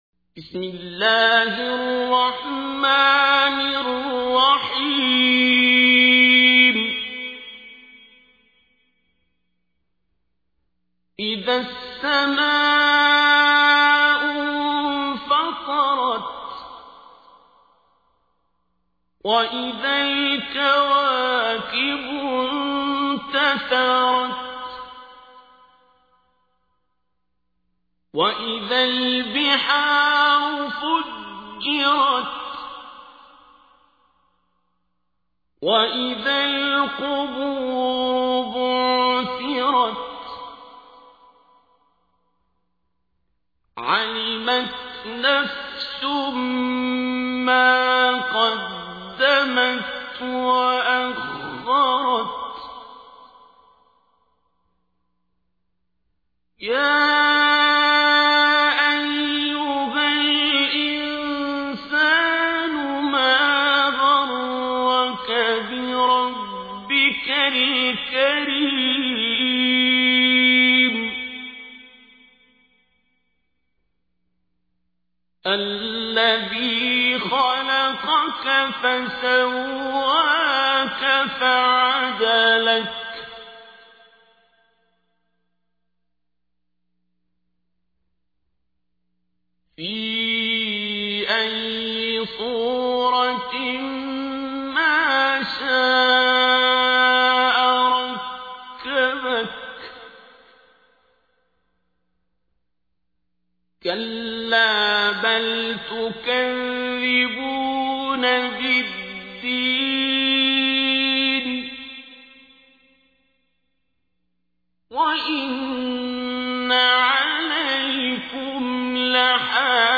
تحميل : 82. سورة الانفطار / القارئ عبد الباسط عبد الصمد / القرآن الكريم / موقع يا حسين